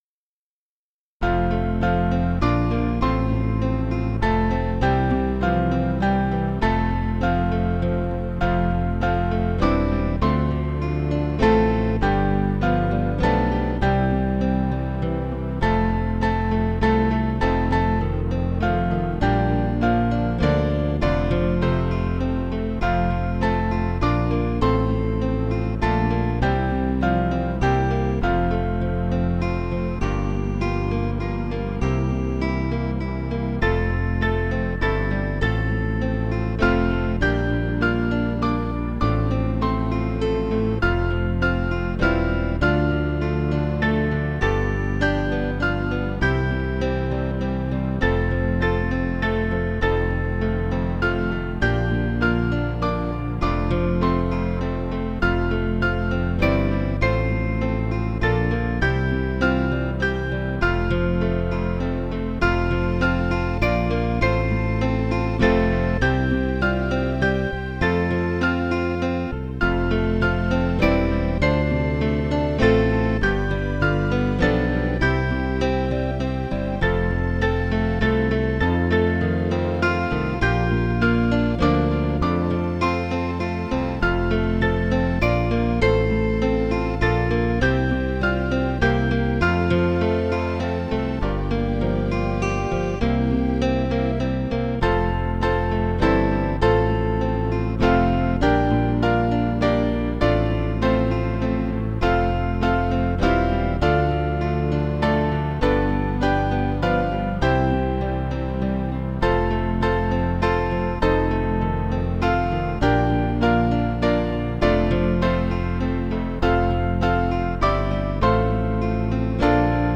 Small Band
Guitar   482.7kb